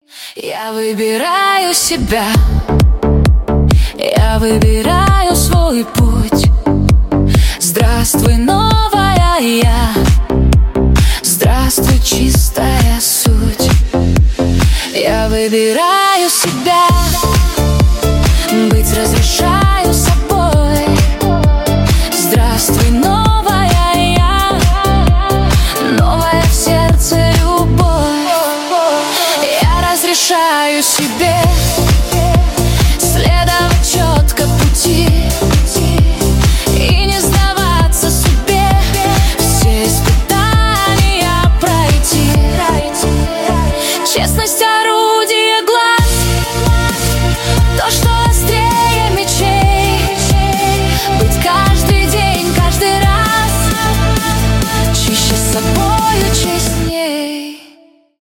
клубные
поп , женские